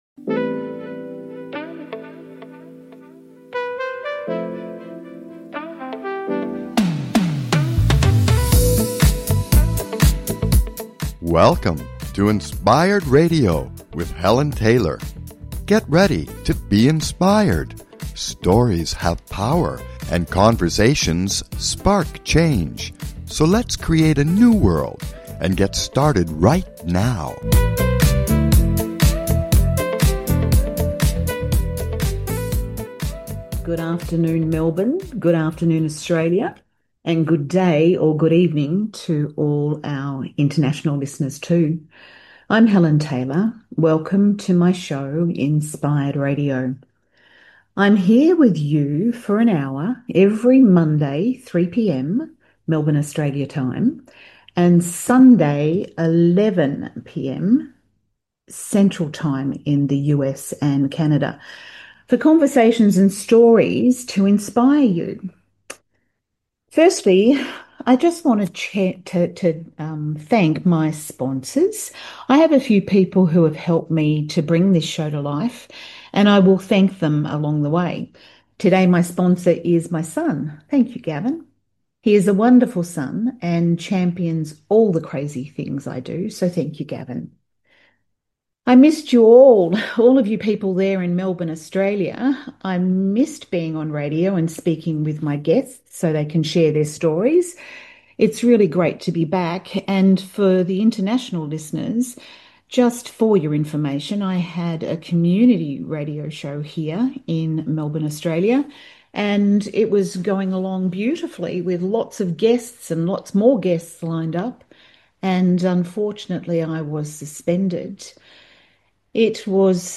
Please consider subscribing to this talk show.
Each week, my guests share their stories, powerful journeys of overcoming change and stepping into a better life.